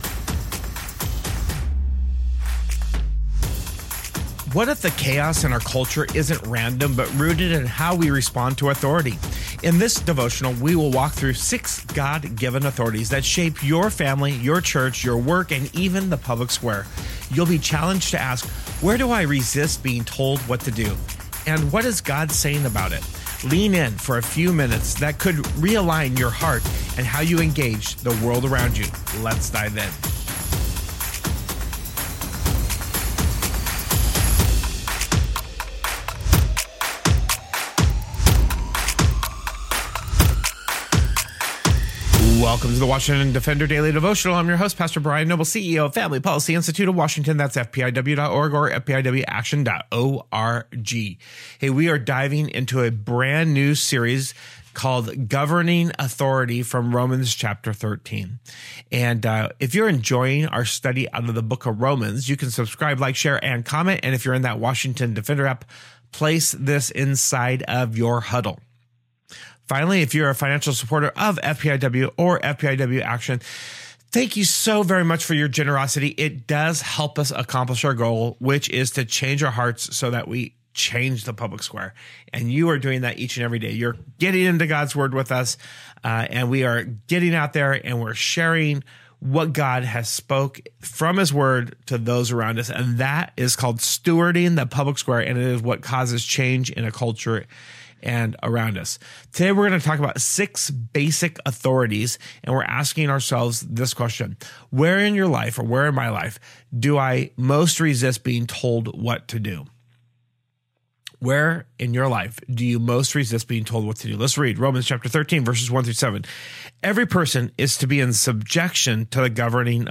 In this devotional, we walk through six God-given authorities that shape your family, your church, your work, and even the public square.